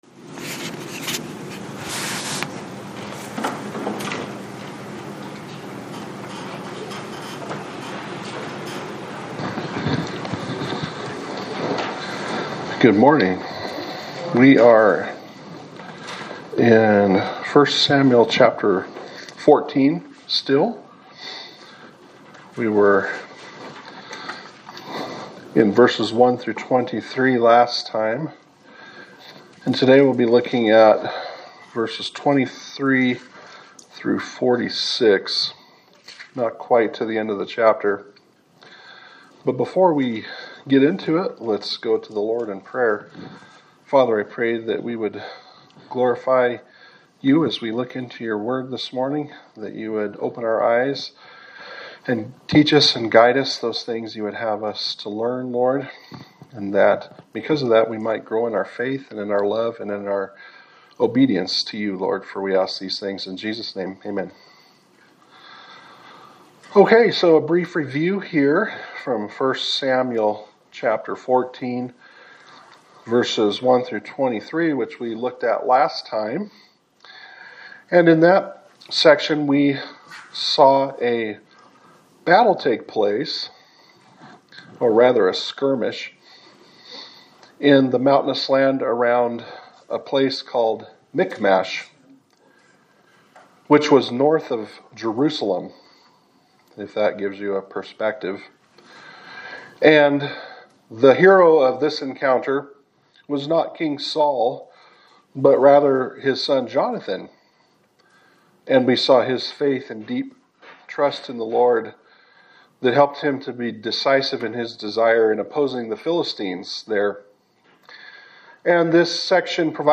Sermon for November 30, 2025
Service Type: Sunday Service